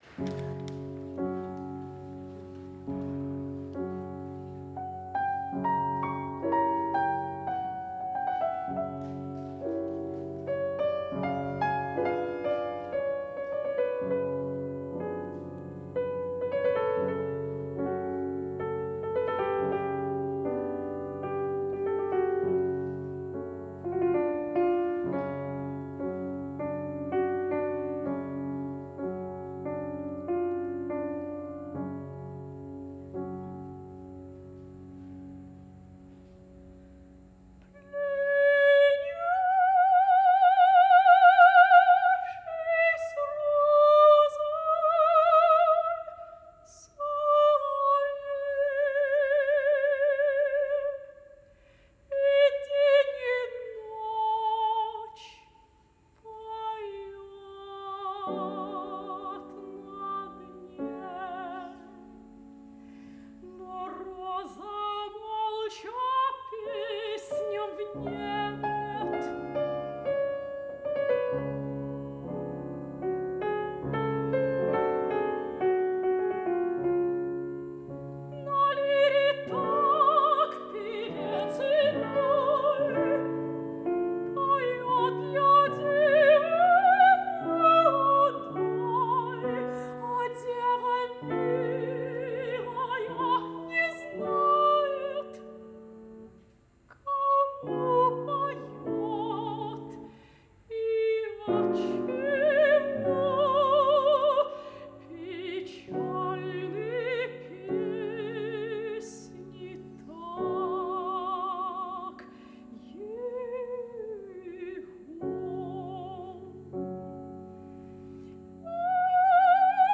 Russian melodies
Nikolaï Rimsky-Korsakov – Plenivshis rozoy solovey (Piano